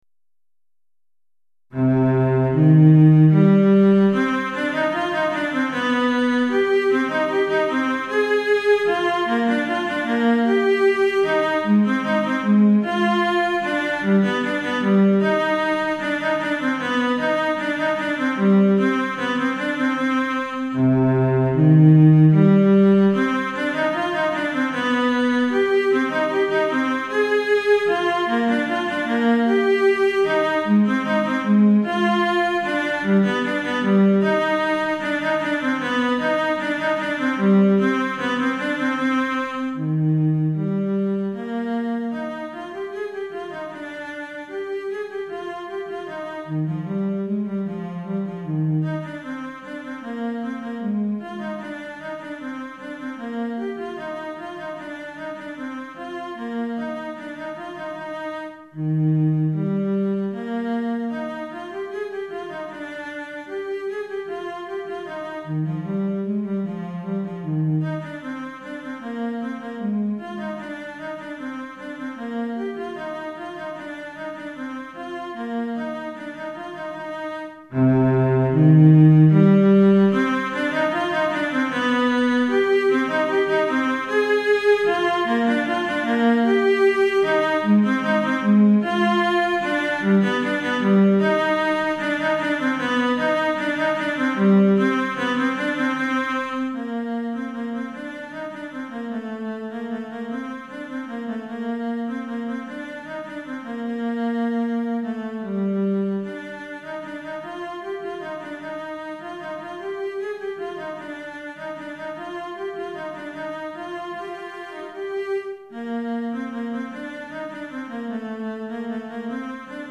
Violoncelle Solo